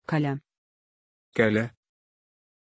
Pronunciation of Kolya
pronunciation-kolya-ru.mp3